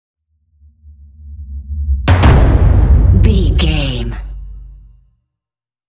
Dramatic whoosh to hit drum
Sound Effects
Atonal
dark
driving
futuristic
intense
tension
woosh to hit